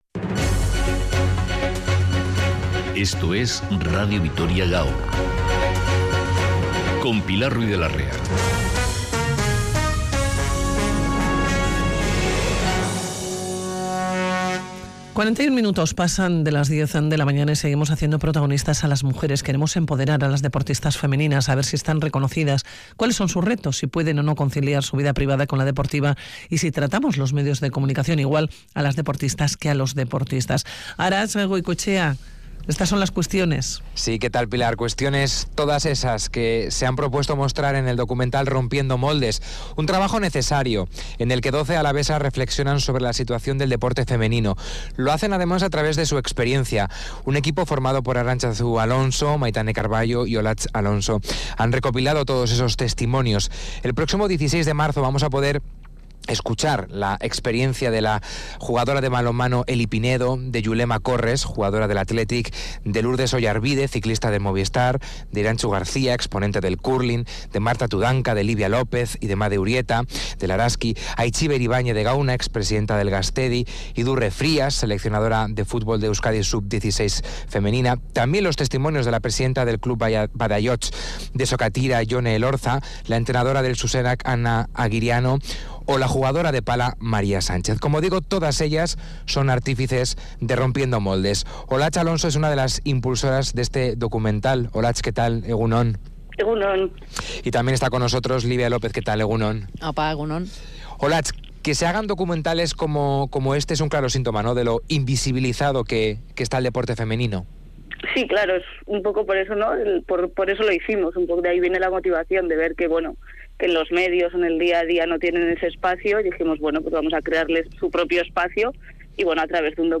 Doce alavesas reflexionan sobre la situación del deporte femenino
Audio: Un reportaje que hace protagonistas a las mujeres y que empodera a las deportistas femeninas. Nos narra cuáles son sus retos, si pueden o no conciliar su vida privada con la deportiva...